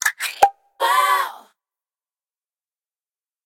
10_Pop.ogg